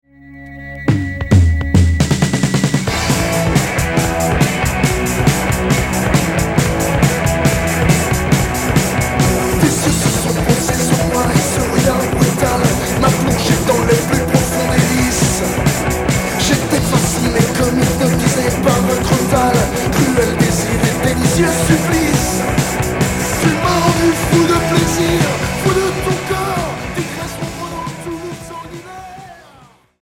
Street punk